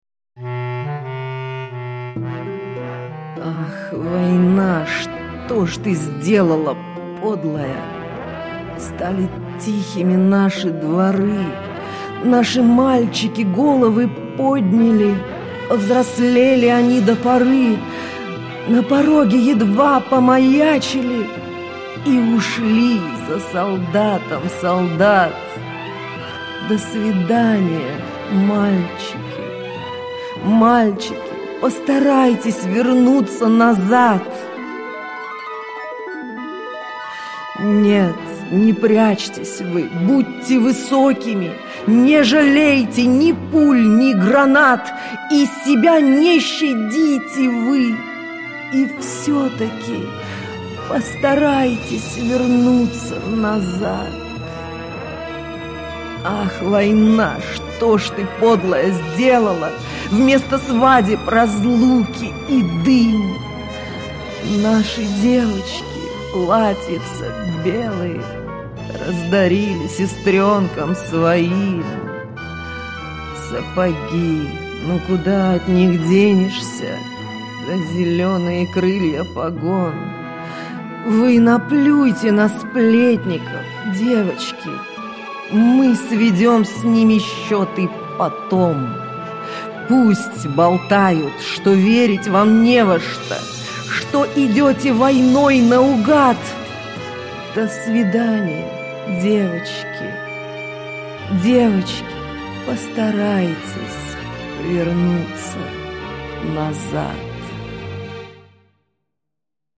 Ее декламация просто замечательная, ее голос проникает в души даже  не особенных любителей поэзии